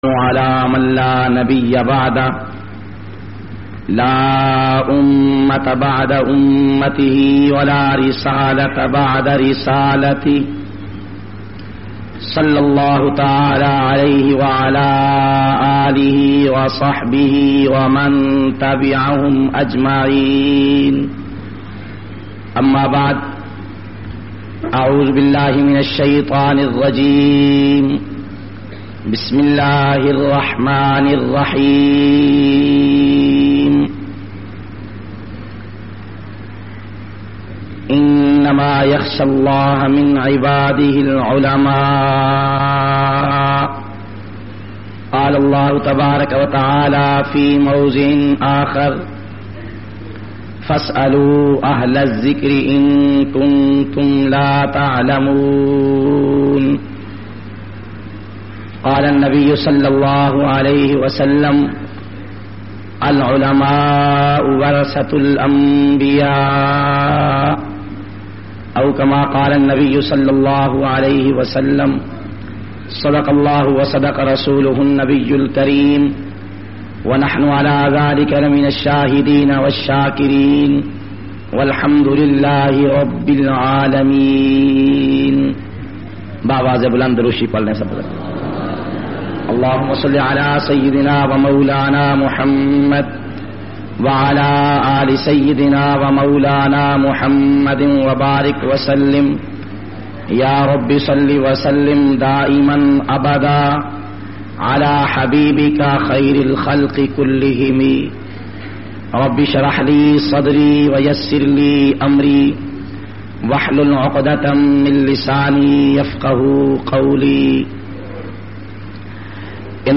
750- Khatm e Bukhari-Dar Ul Uloom KabirWala.mp3